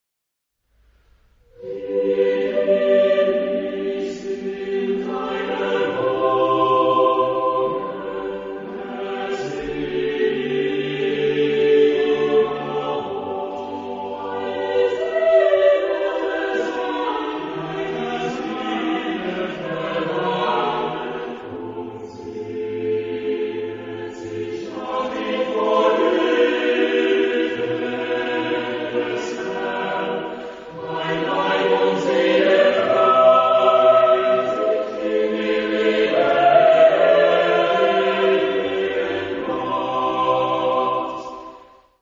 Genre-Style-Form: Psalm ; Sacred ; Romantic
Mood of the piece: flowing ; calm
Tonality: G major